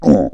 Hit Grave.wav